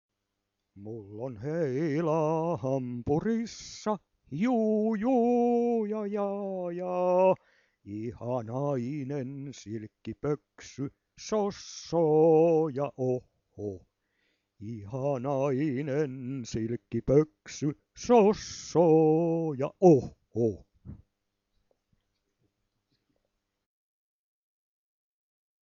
VANHOJA PIIRILEIKKILAULUJA